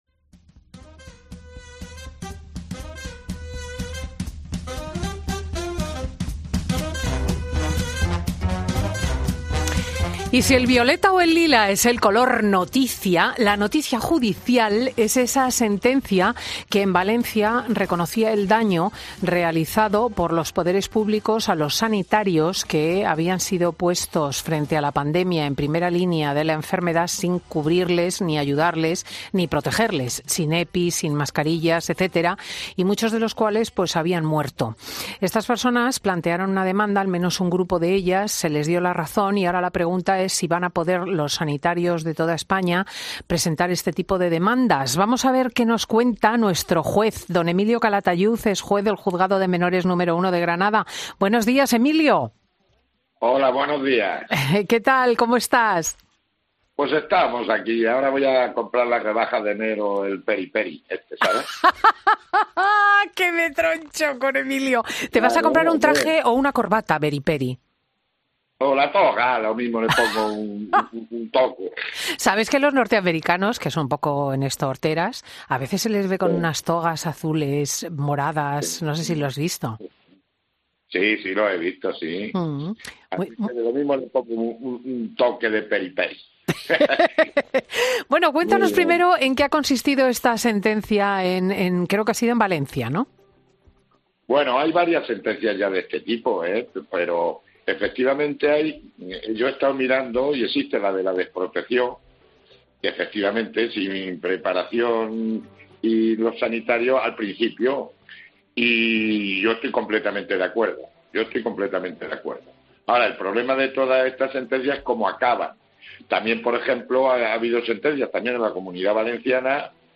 Emilio Calatayud, juez de menores de Granada, vuelve a Fin de Semana con Cristina. Hoy, por una condena judicial muy importante a la Generalitat Valenciana